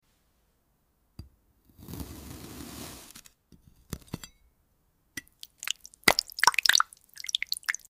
Glass + frozen fruit = sound effects free download